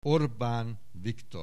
Aussprache Aussprache
ORBANVIKTOR.wav